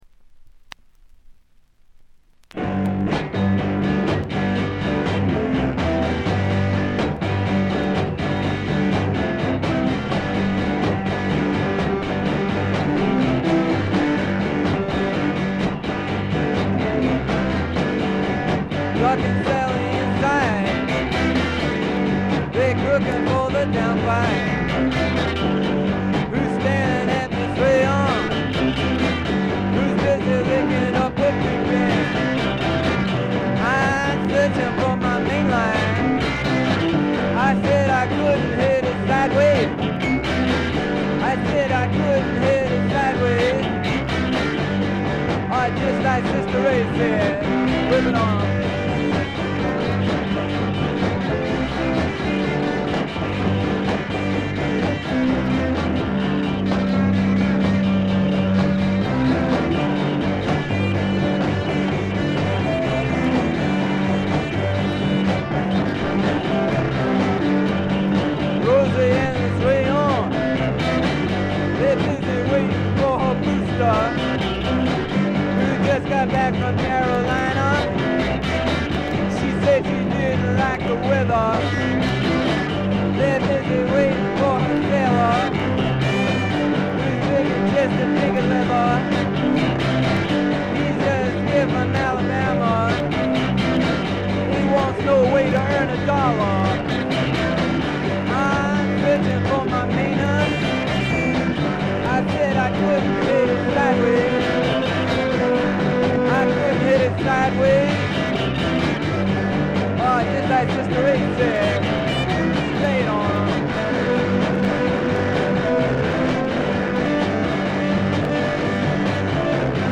試聴曲は現品からの取り込み音源です。
※B2途中まで。中断直前に2回ほどと少し前に1回プツ音。ほとんど気にならないというか気づかないかも。